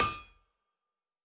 Blacksmith 01.wav